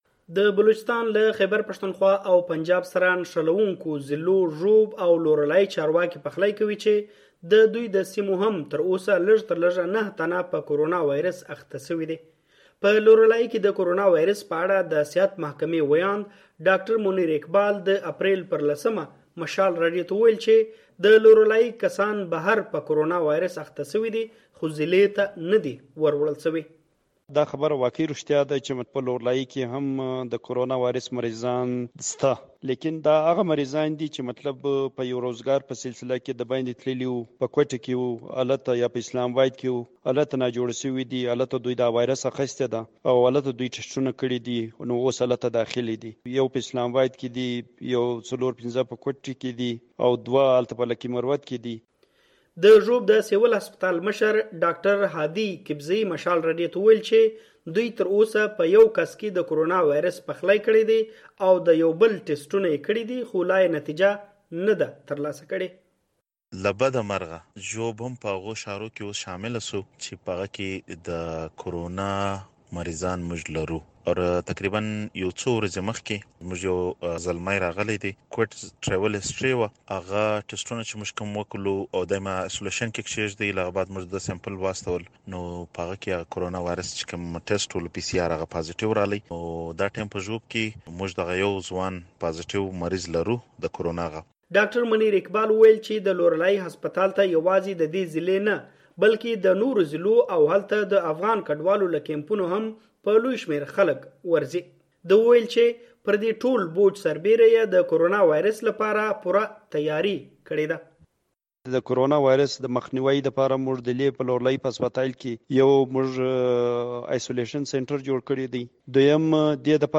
په لورالايي‌ او ږوب کې د کورونا وایرس په اړه رپورټ دلته واوری